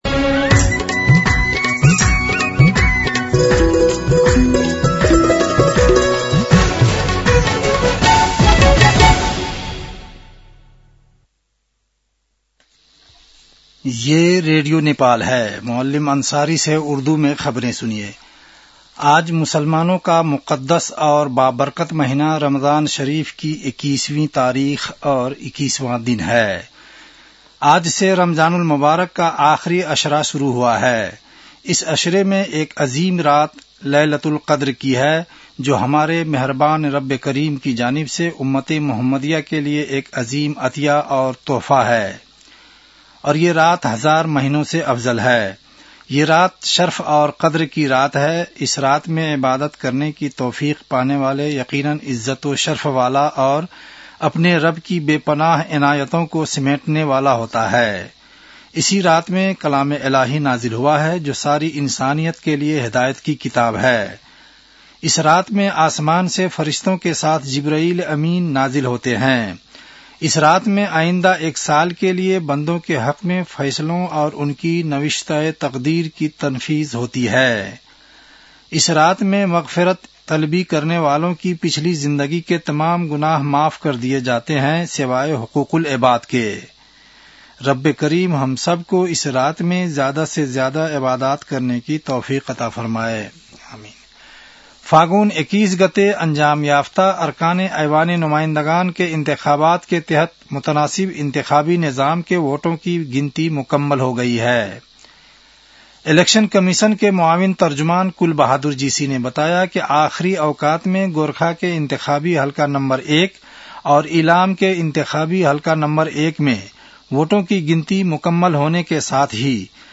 उर्दु भाषामा समाचार : २७ फागुन , २०८२